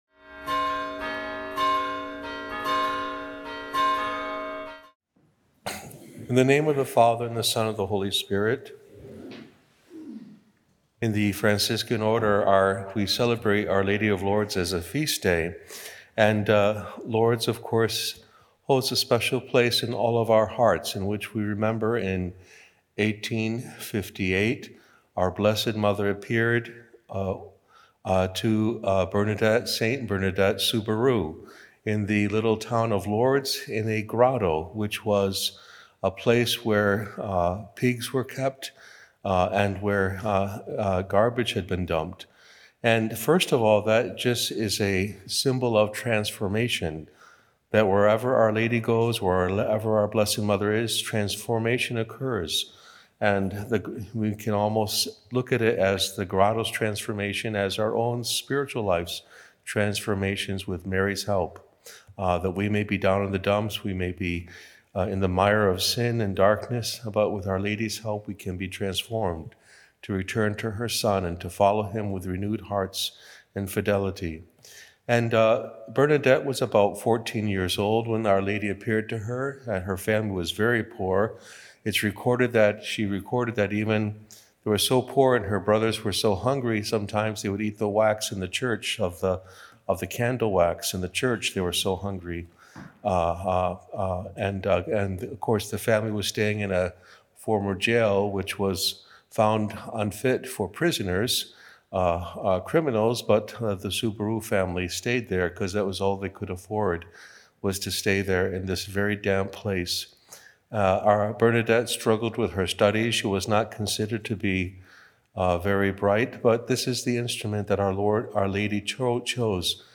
Mary Transforms Lourdes and Hearts- Feb 11 – Homily